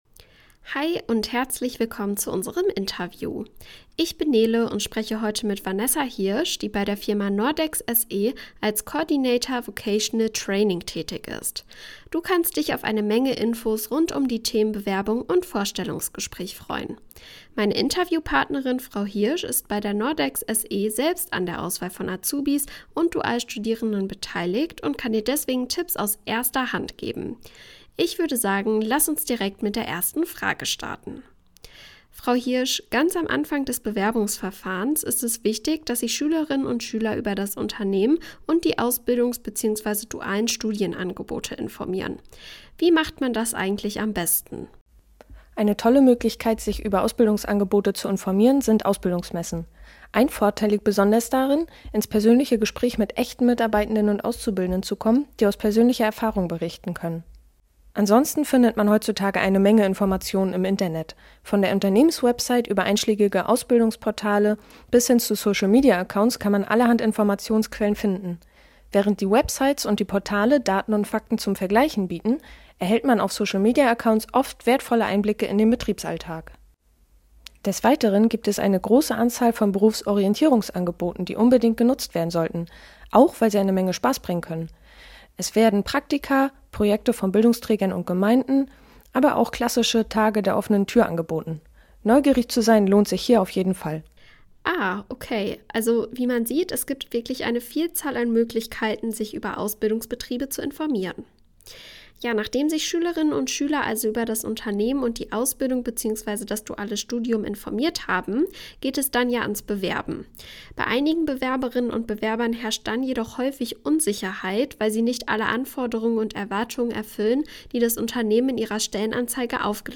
Interview
interview-nordex.mp3